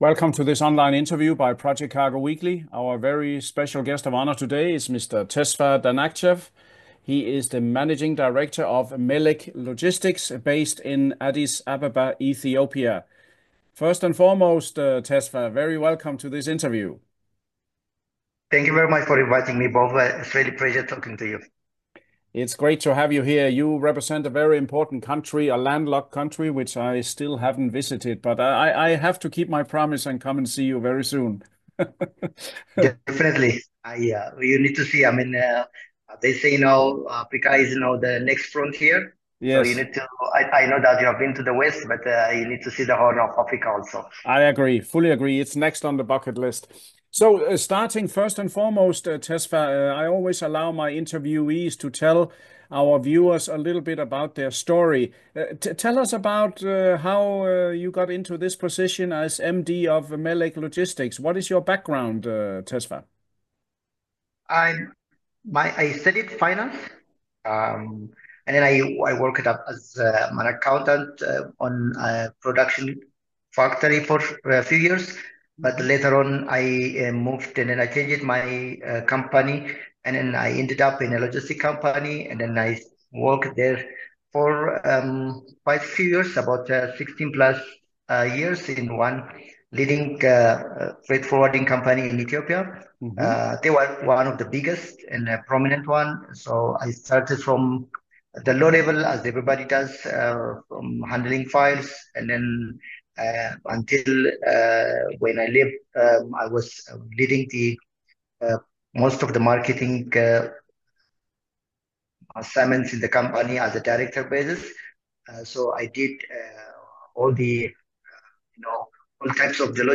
Melehk Logistics – Interview